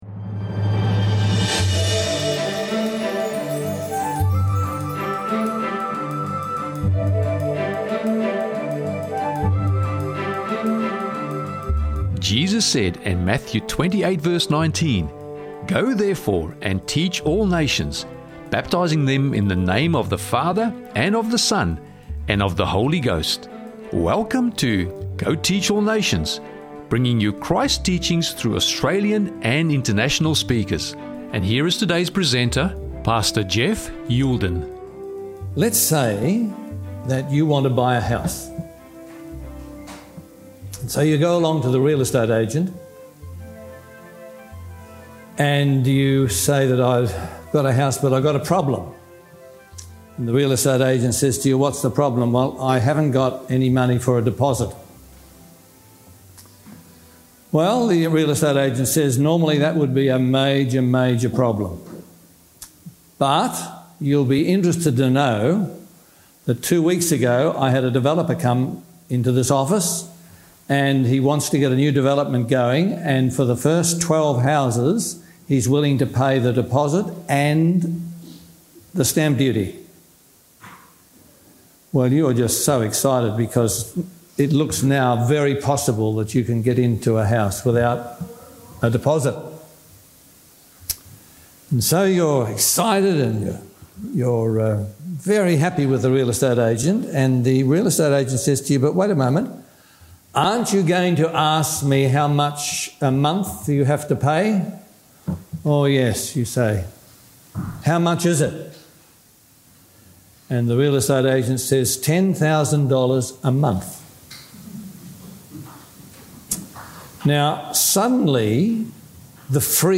Justified, Sanctified, Transformed – Sermon Audio 2608
This message was made available by the Stanmore Seventh-day Adventist church.